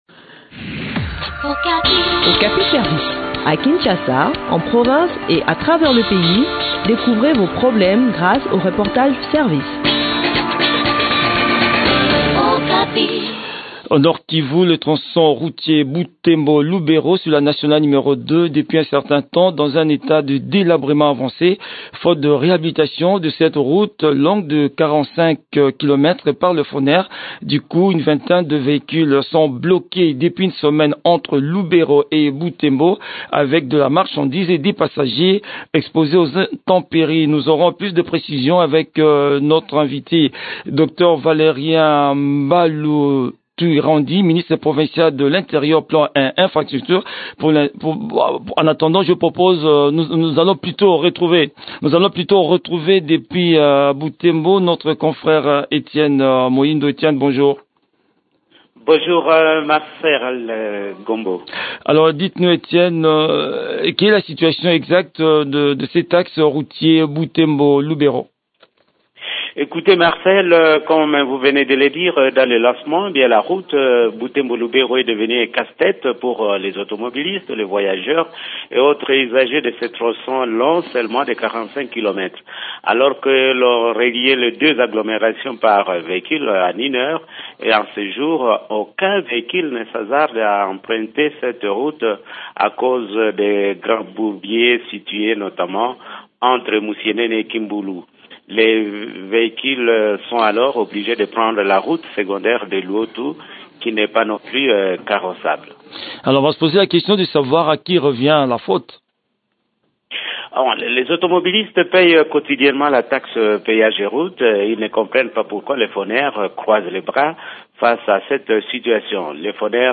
Valérien Mbalu Twirandi, ministre provincial de l’Intérieur, plan et infrastructure.